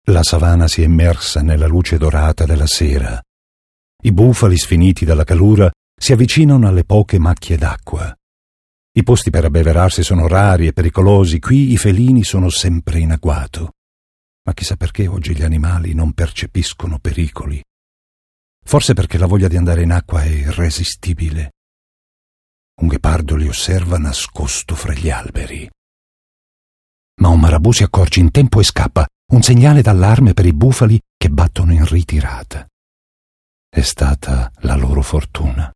sprecherprobe.mp3